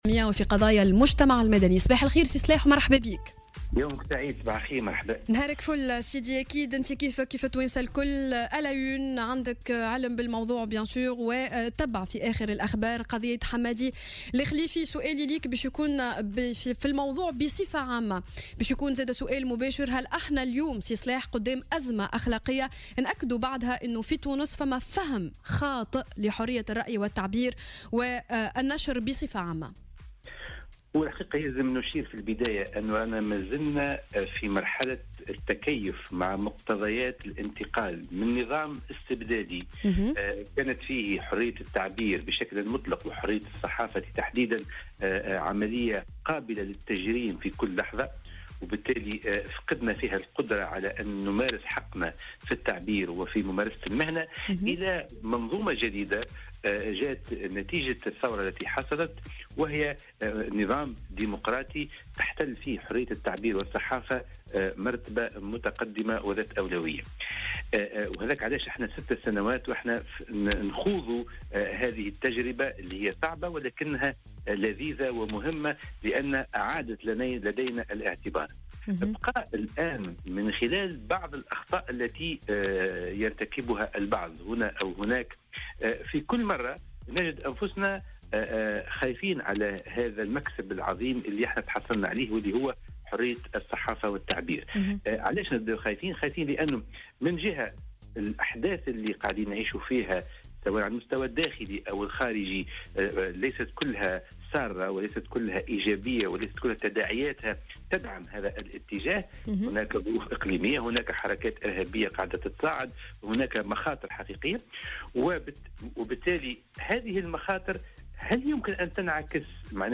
مداخلة له في صباح الورد على الجوهرة